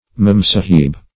Search Result for " mem-sahib" : The Collaborative International Dictionary of English v.0.48: memsahib \mem"sa`hib\, mem-sahib \mem"-sa`hib\(m[e^]m"s[aum]`[i^]b), n. [Hind. mem-s[=a]hib; mem (fr. E. ma'am) + Ar.